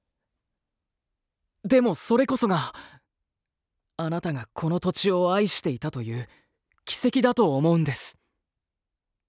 穏やかさと知的好奇心に溢れた、ルポライターの青年。